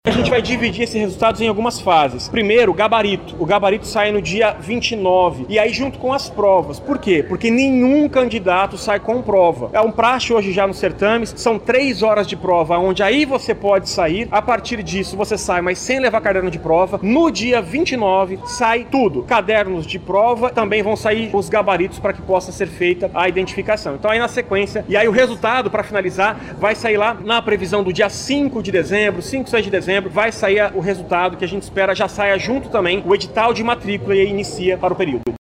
SONORA-2-VESTIBULAR-UEA-.mp3